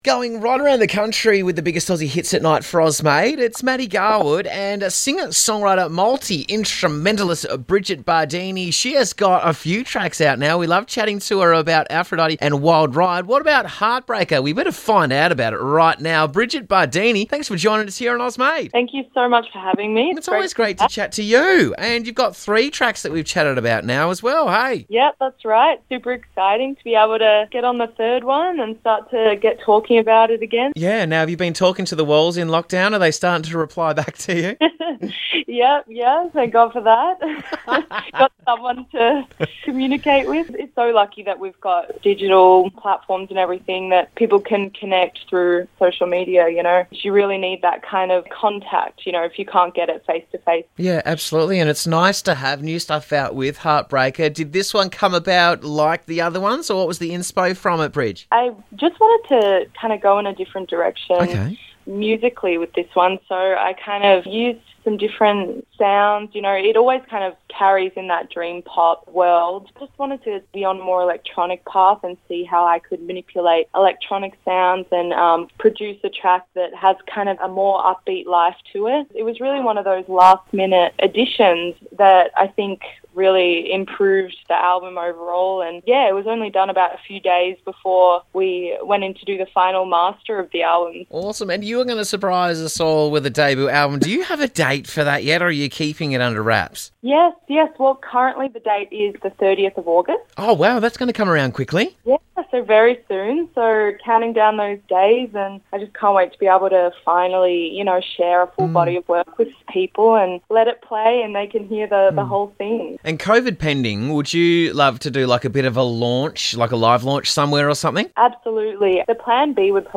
Upcoming singer-songwriter and multi-instrumentalist
A luscious and sprawling soundscape
quite an upbeat offering